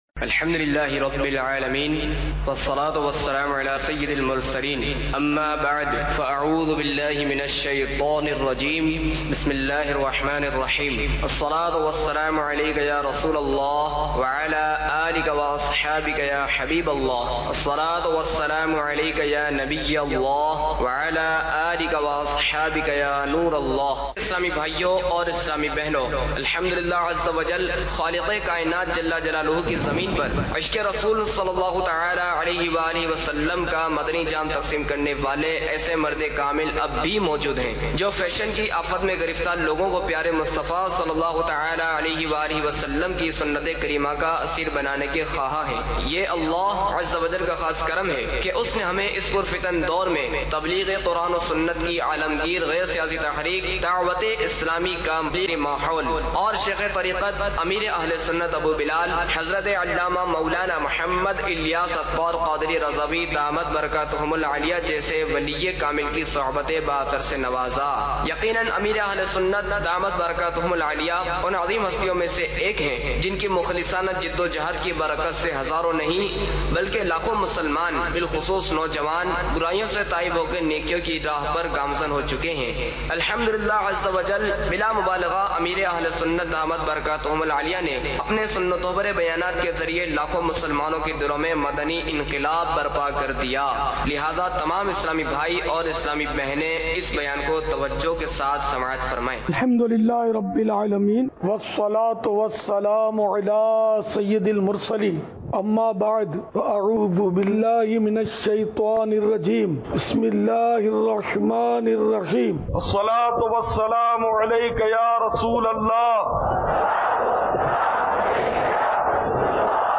آڈیو بیان – صحابہ کرام علیہم الرضوان کا مقام عشق